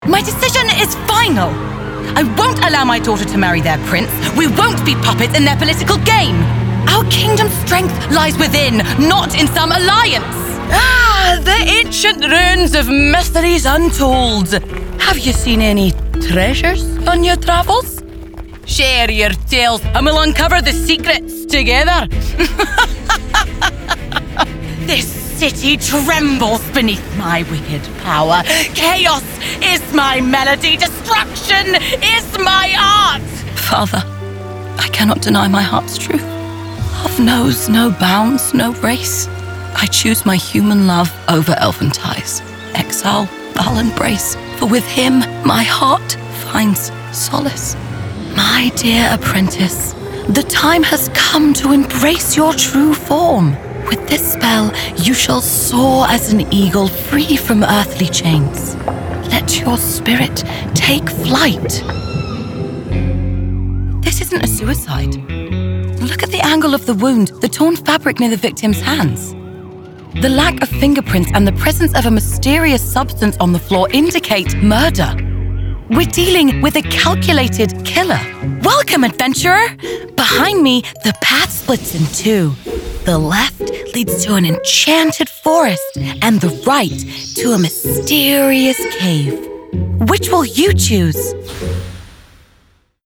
Inglés (Británico)
Versátil, Amable, Natural
Her voice is youthful, husky, relatable, and authentic, and her natural accent is a London/Estuary accent.